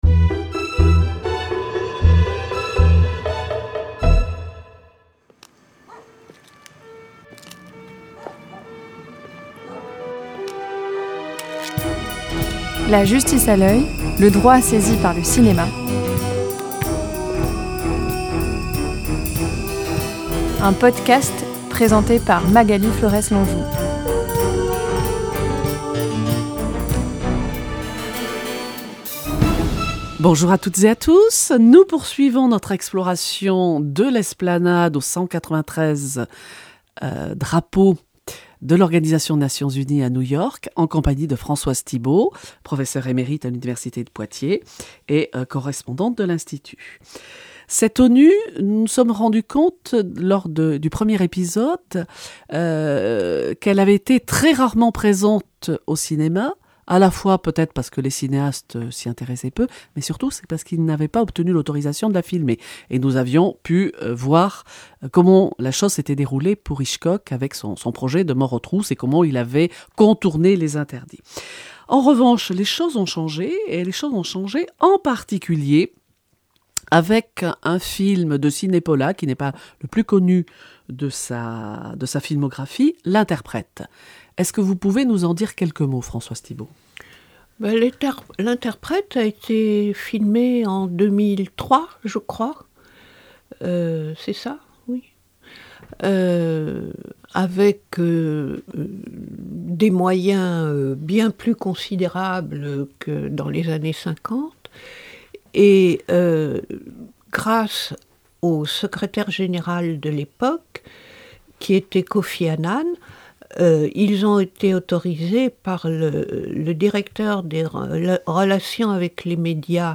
Les In et les Off | Saison 5 – épisode 2/4 – Entretien